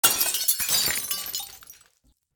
* Downmix stereo effects to mono
glass_break3.ogg